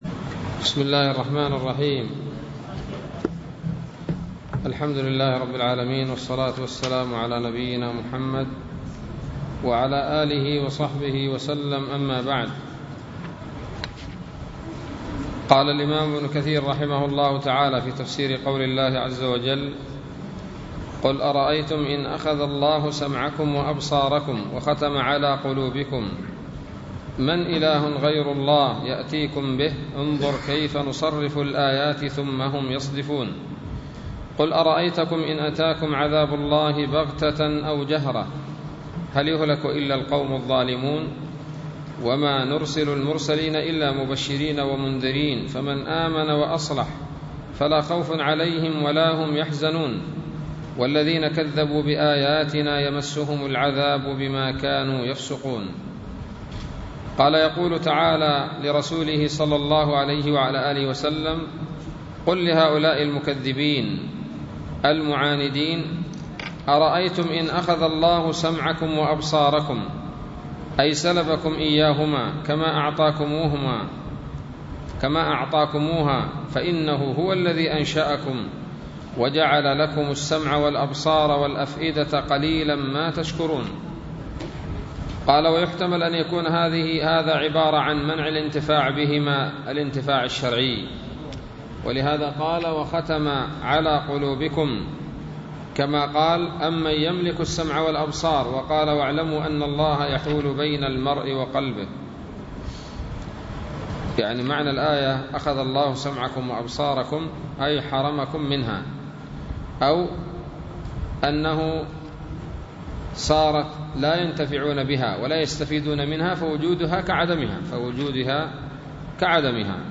الدرس العاشر من سورة الأنعام من تفسير ابن كثير رحمه الله تعالى